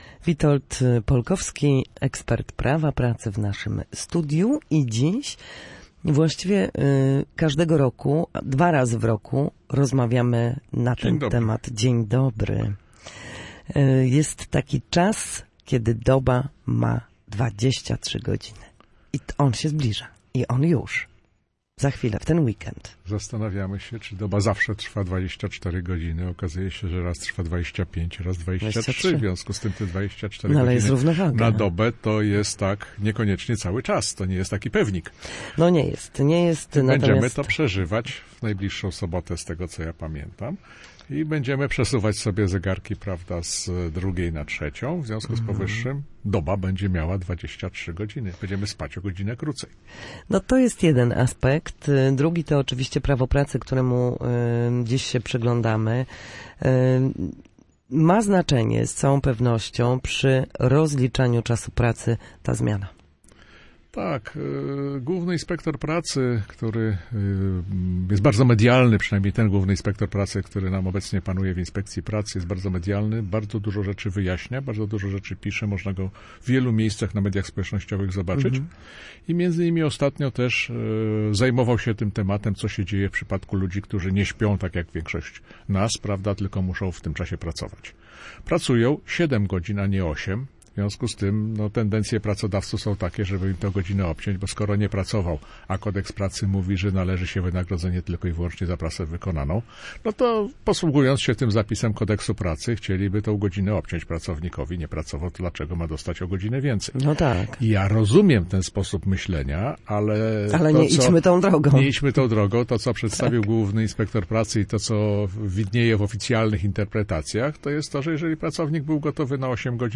W każdy wtorek po godzinie 13:00 na antenie Studia Słupsk przybliżamy Państwu zagadnienia dotyczące prawa pracy.